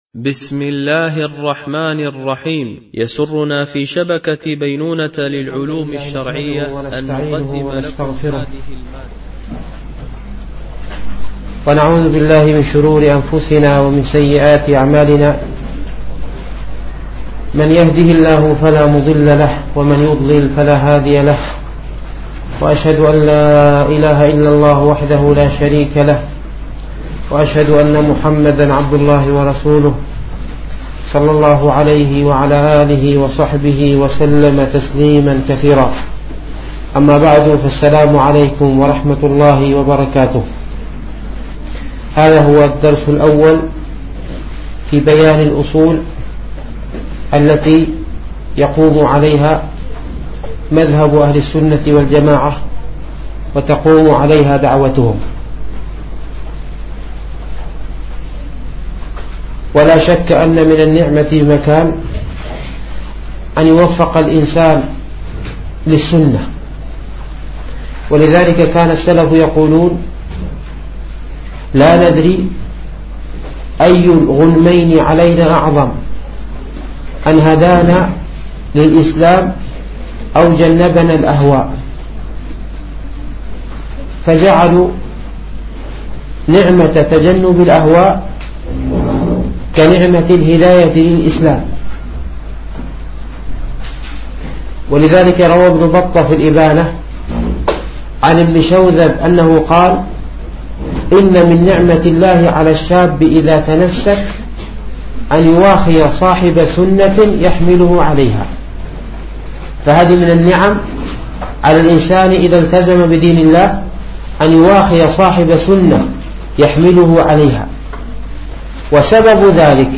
مهمات في السيرة ـ الدرس الاول
التنسيق: MP3 Mono 22kHz 32Kbps (CBR)